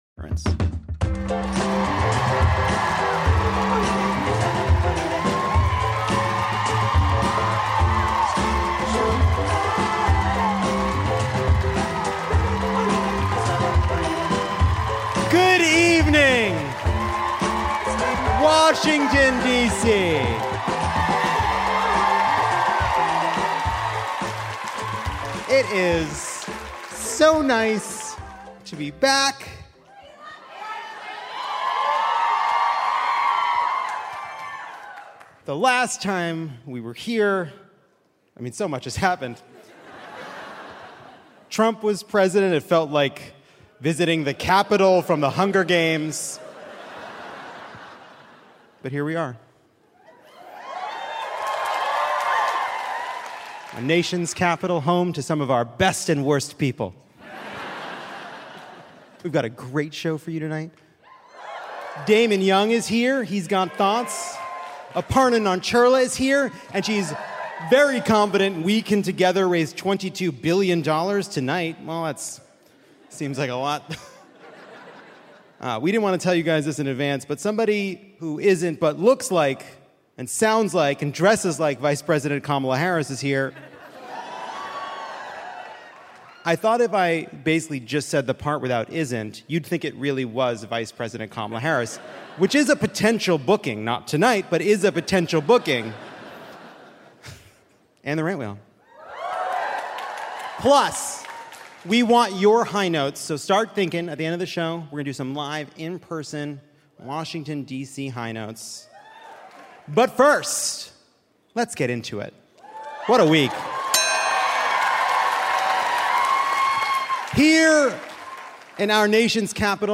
Live from the Lincoln Theatre, Lovett or Leave It storms Washington, D.C. and seizes its jumbo slices.